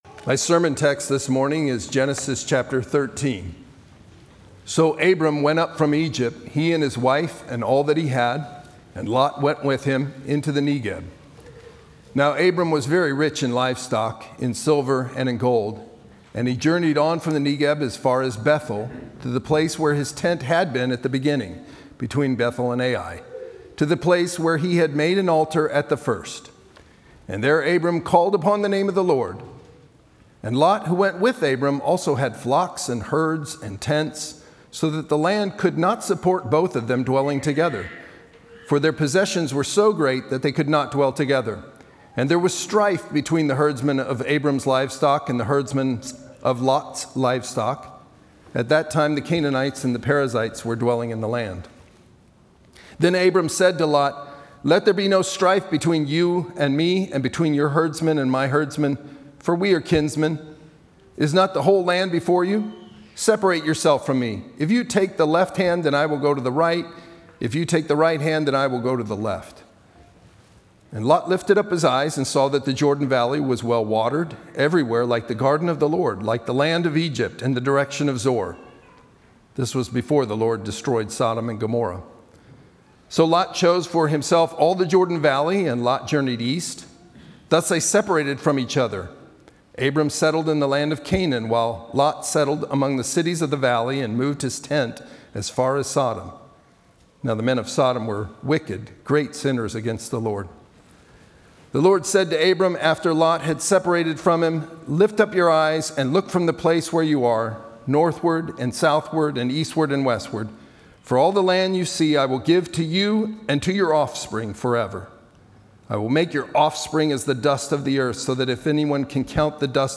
Sermons on Genesis Passage: Genesis 13:1-18 Service Type: Sunday worship